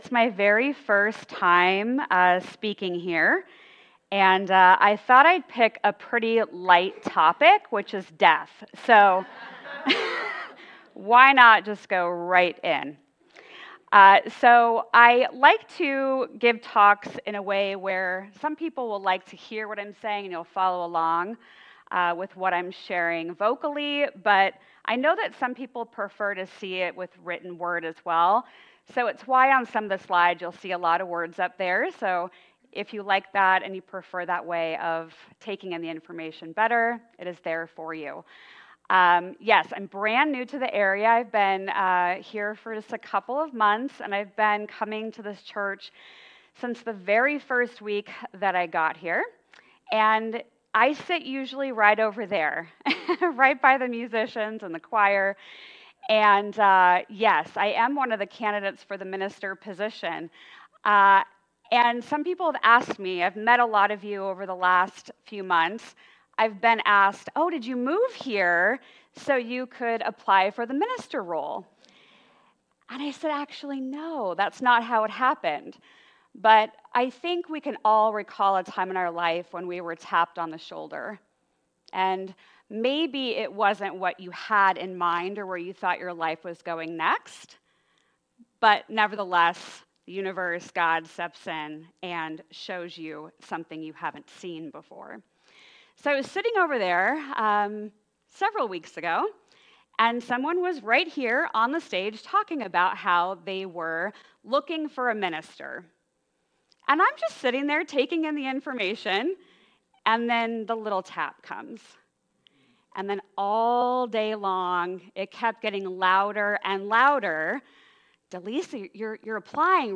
The audio recording (below the video clip) is an abbreviation of the service. It includes the Message, Meditation, and Featured Song.